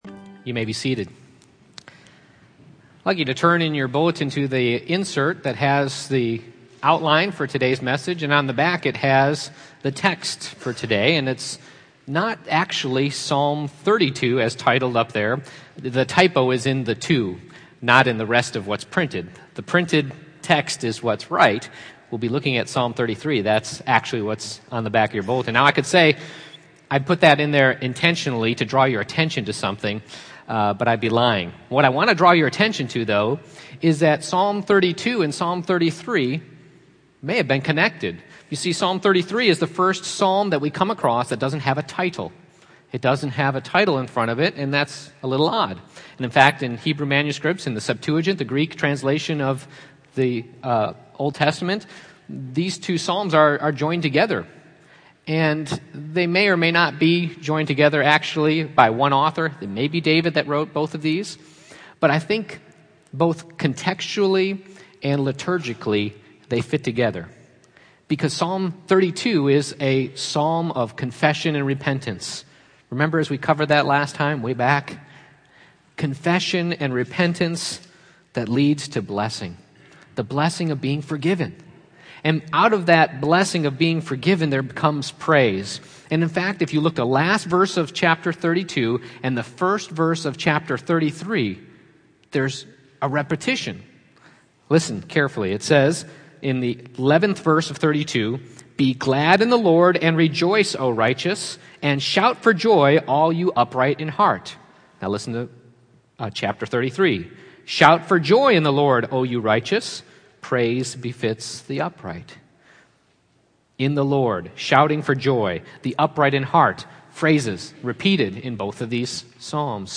Passage: Psalm 33:1-22 Service Type: Morning Worship